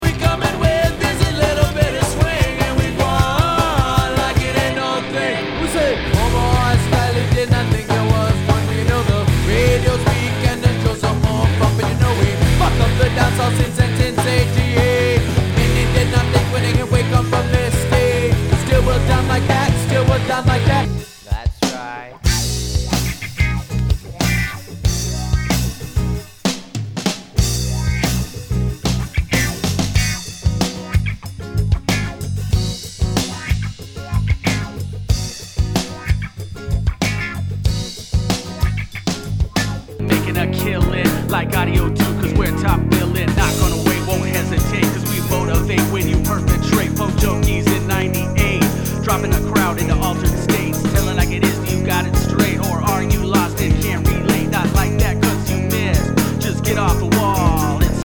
ROCK/POPS/INDIE